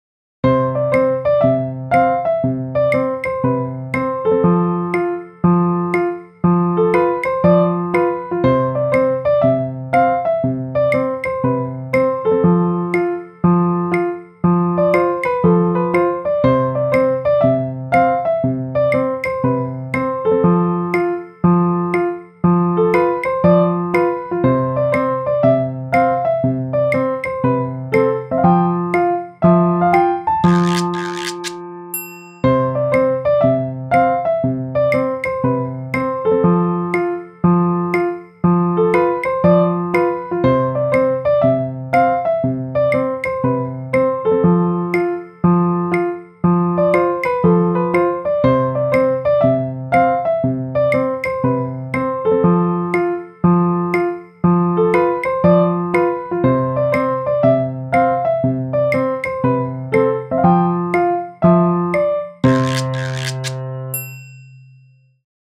heartwarmingLOOP OGG
ぽかぽか陽気の日、ほのぼの、リラックス、ふんわりのイメージで作曲しました。